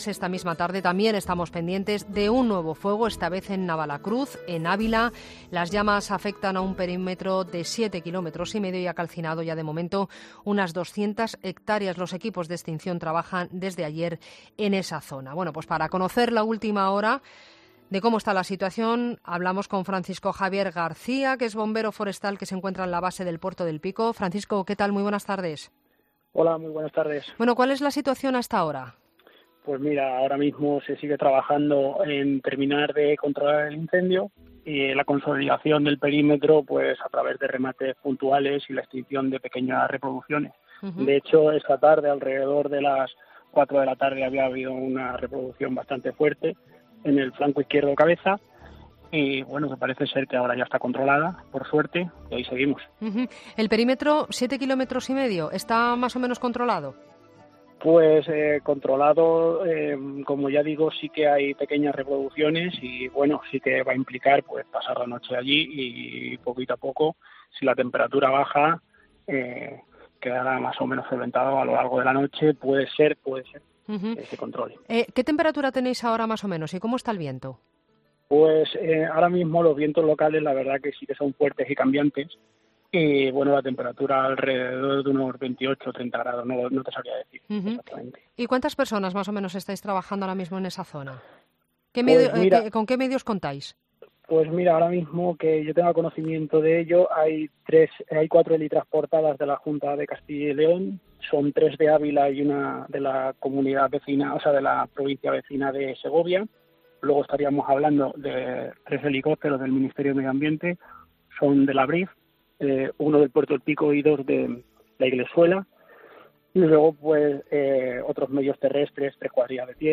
Los vientos locales son fuertes y cambiantes y la temperatura esta alrededor de unos 28 o 30 grados", explica el bombero.